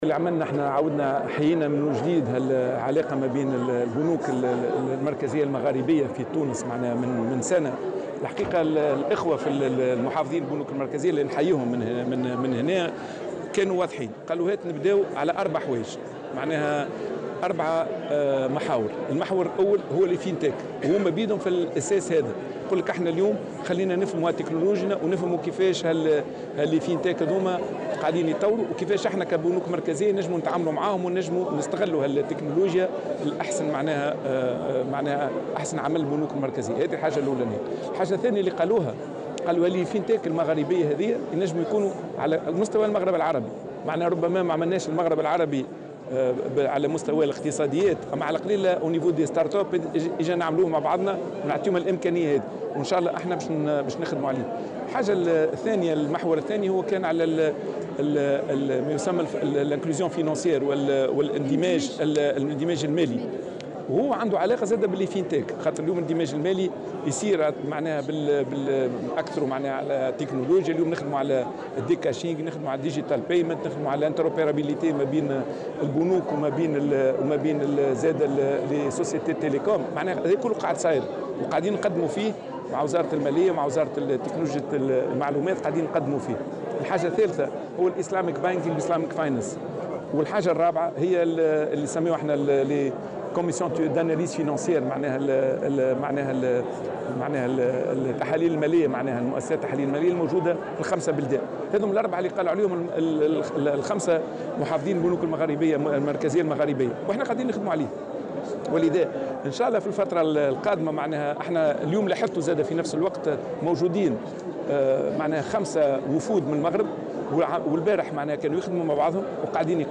وأضاف في تصريح لمراسلة "الجوهرة أف أم" على هامش افتتاح المؤتمر السنوي لنادي فوركس تونس، إن مجالات التعاون تهم مؤسسات التحاليل المالية والاندماج المالي وتطوير استعمال الخدمات الرقمية.